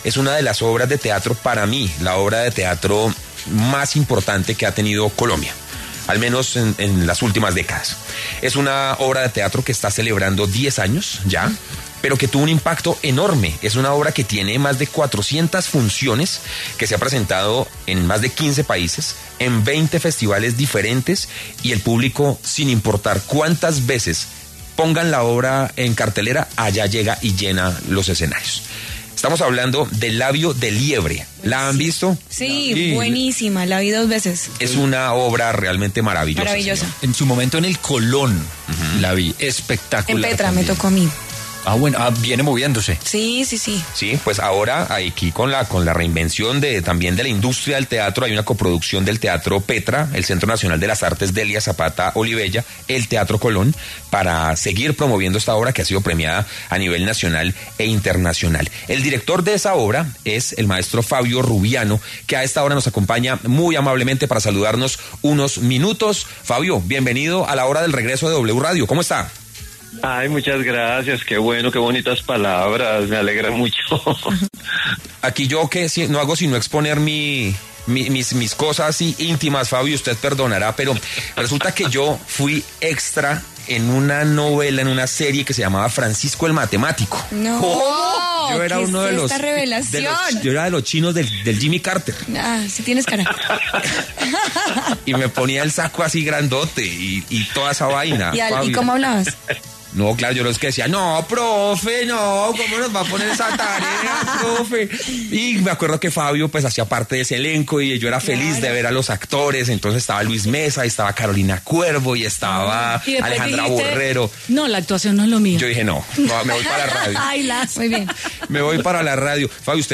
Fabio Rubiano, creador de ‘Labio de Liebre’ y fundador del Teatro Petra, conversó con La Hora del Regreso sobre esta icónica obra que regresa a los escenarios y lo que ha significado en su trayectoria.